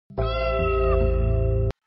猫咪 | 健康成长
maojiao.mp3